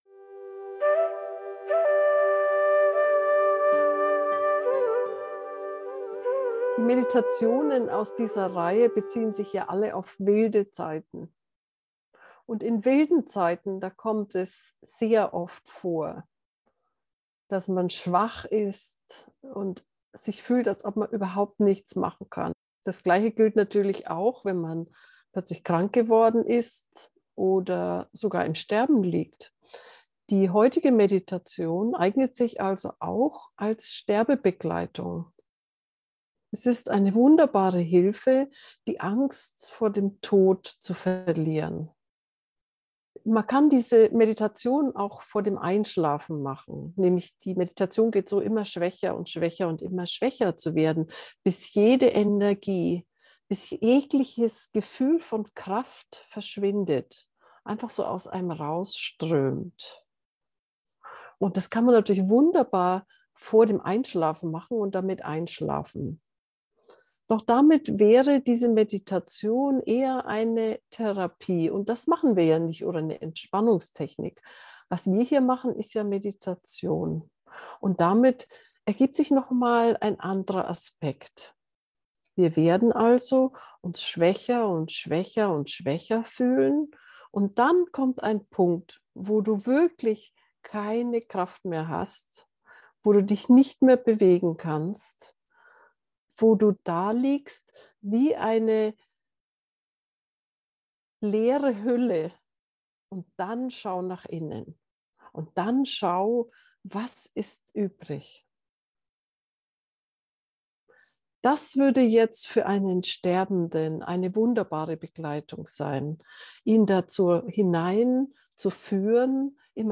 erschoepfung-meditation-schwach-gefuehrte-meditation-2